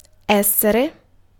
Ääntäminen
US : IPA : /ˈgɛt/